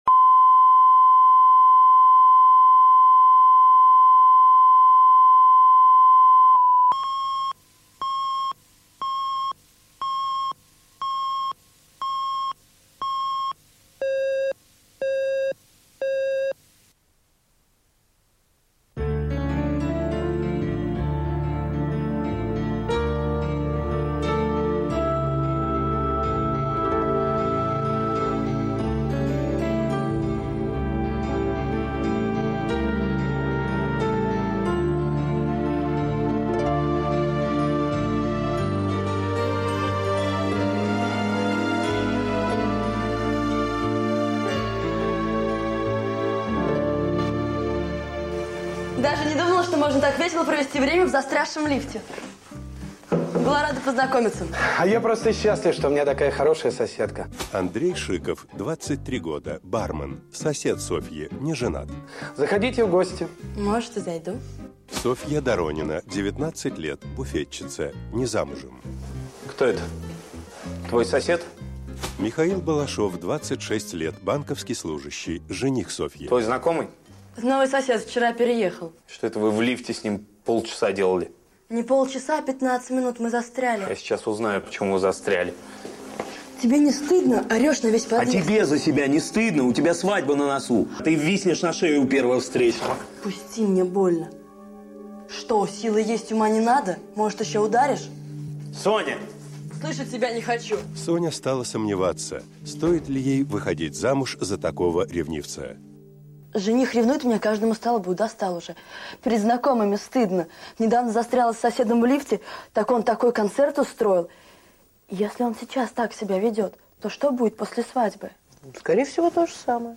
Аудиокнига Ошибка молодости | Библиотека аудиокниг